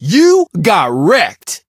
brock_kill_vo_04.ogg